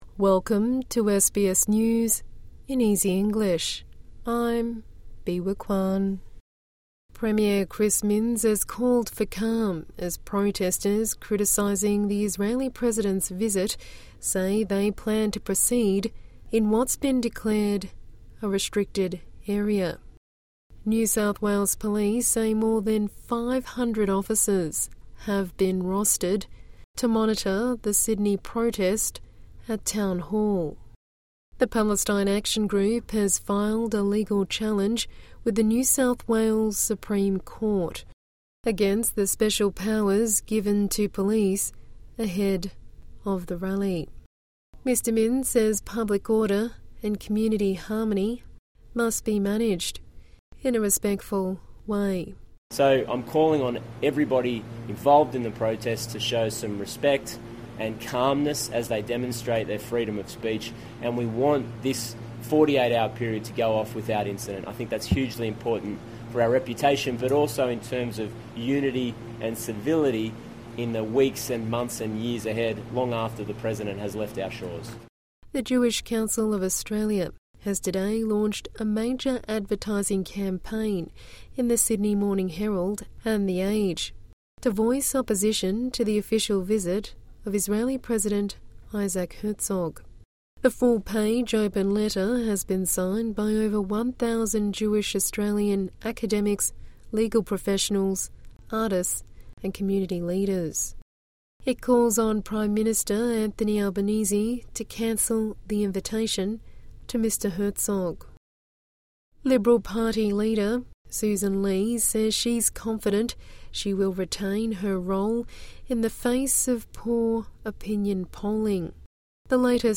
A daily 5-minute news bulletin for English learners and people with a disability.